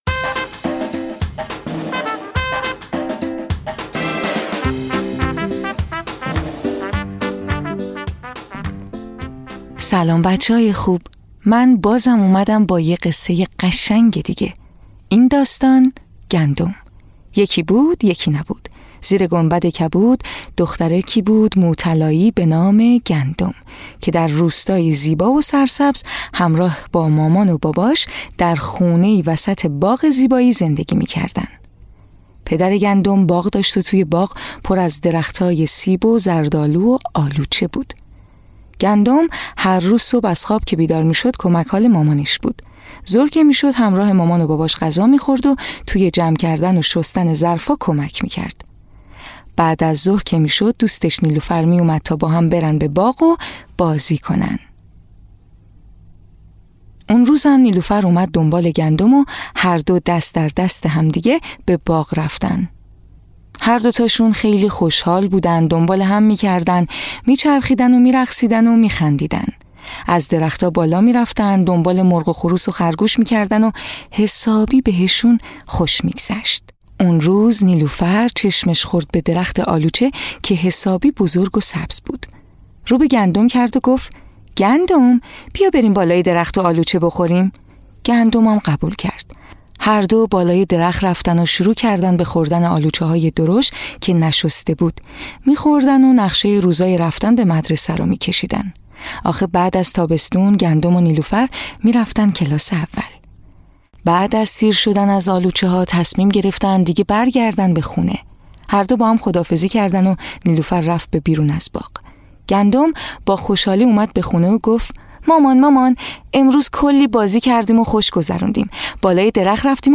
قصه‌های کودکانه صوتی - این داستان: گندم
تهیه شده در استودیو نت به نت